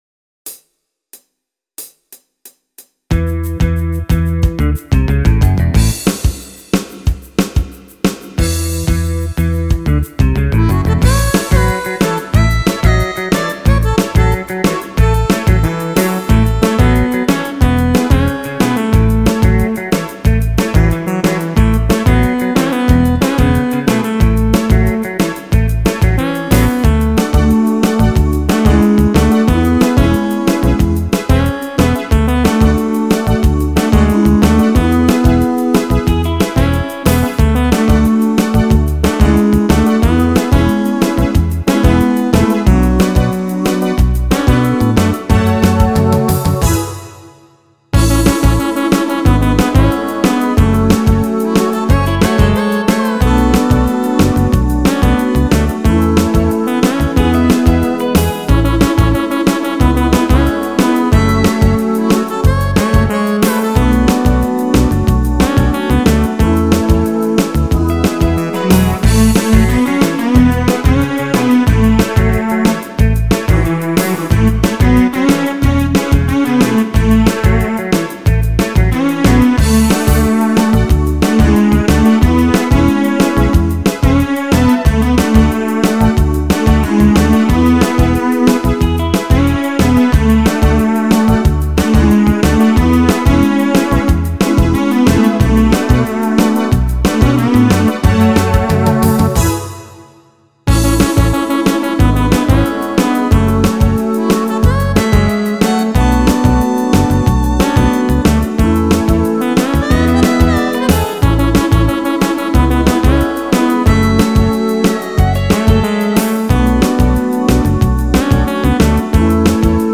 [Pop/Rock List]